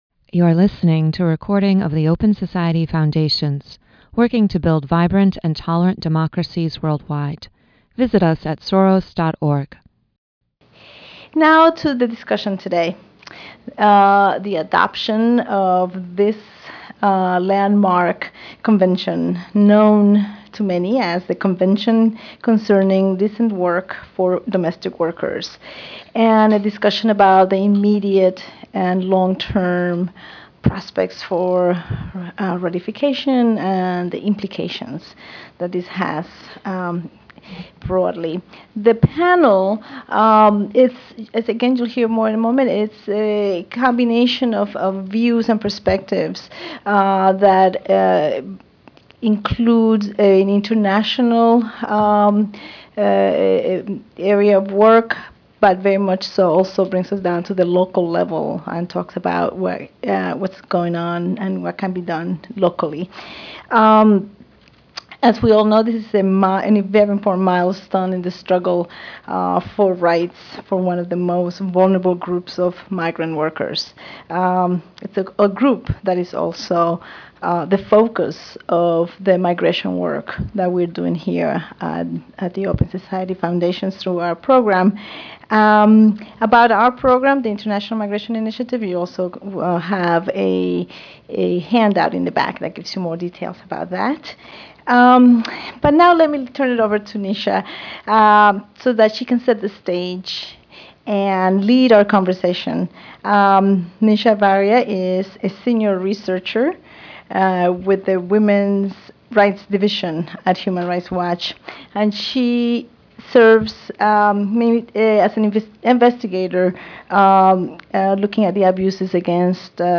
Protecting Domestic Workers Worldwide (September 21, 2011) Download MP3 A panel of experts discuss the immediate and long-term implications of the new Domestic Workers Convention that was adopted by the International Labor Organization (ILO) this June. This forum aims to raise awareness on the treaty and its provisions and serve as a platform to initiate a discussion of its implementation.